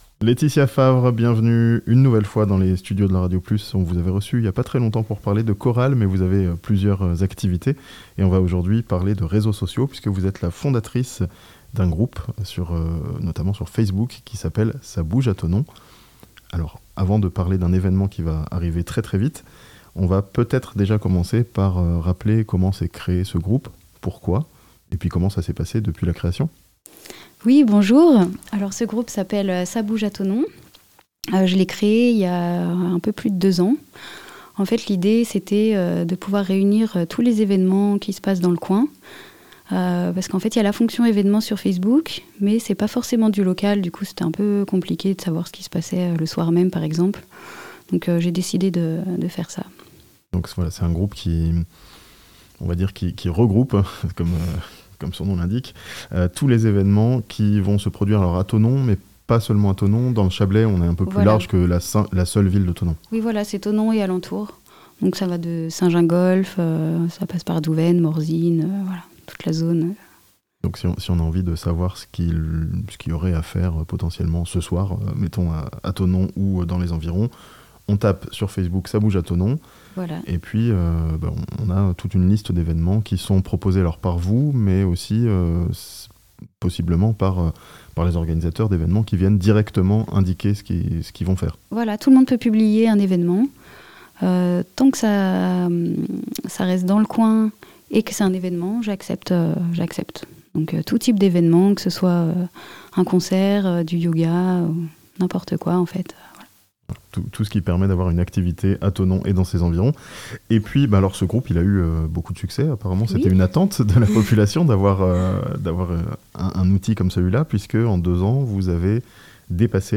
Pour fêter ses 10 000 followers, un groupe Facebook organise une soirée à Thonon (interview)